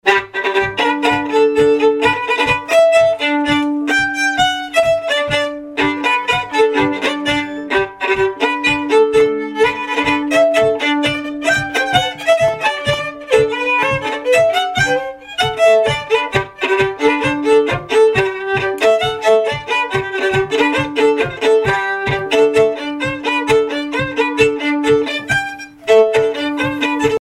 Pièces instrumentales à plusieurs violons
Pièce musicale inédite